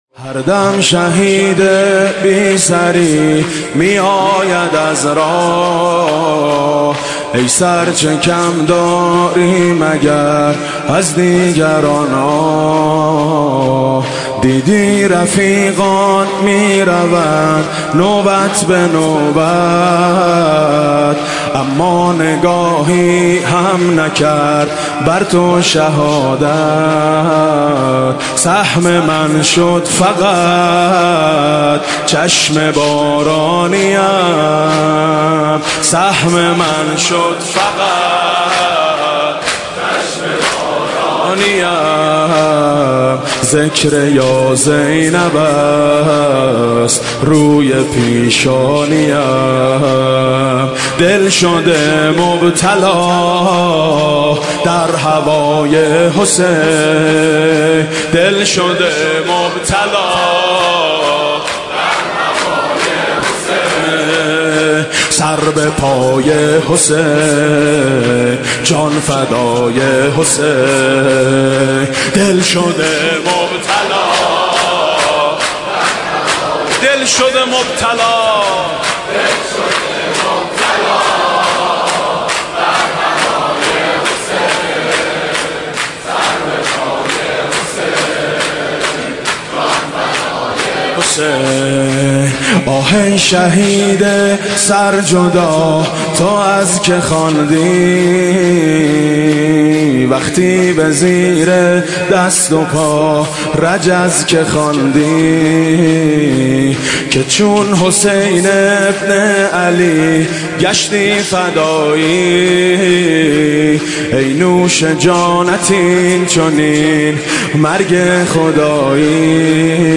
مداحی
نوحه
شور